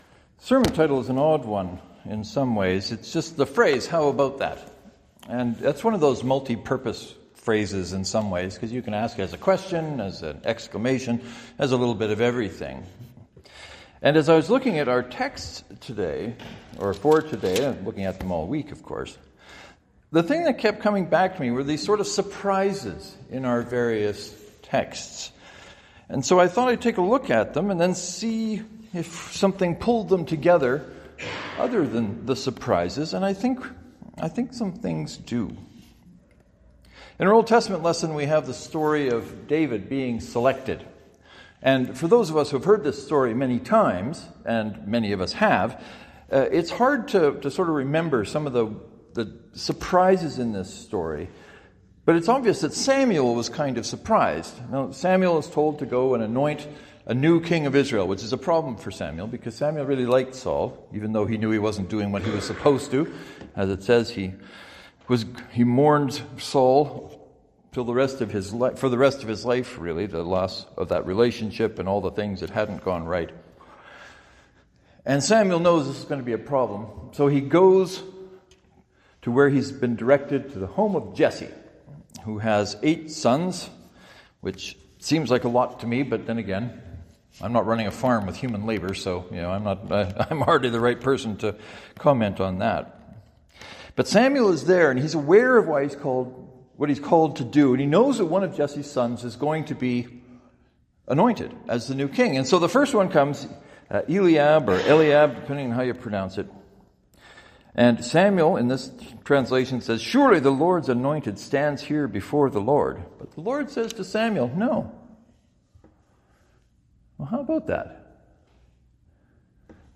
This Sunday I tried to help us see the surprises and think about what they have to say to us.